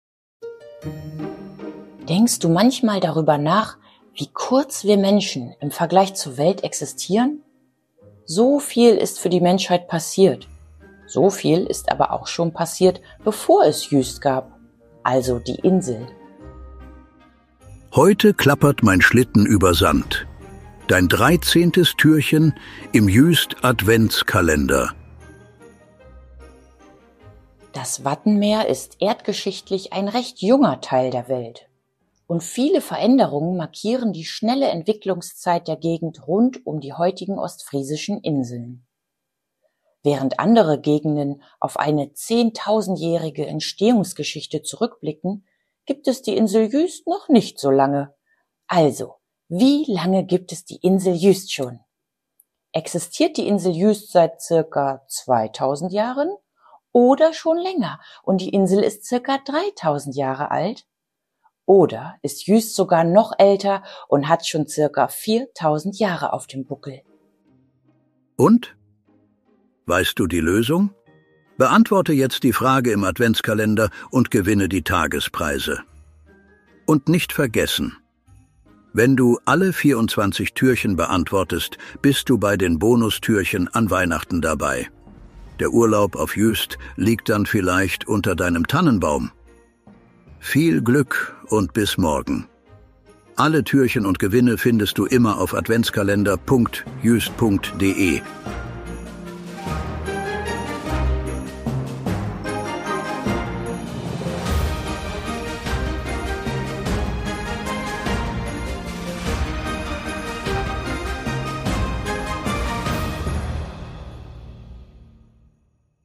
Eingesprochen wird der Adventskalender von vier